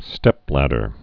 (stĕplădər)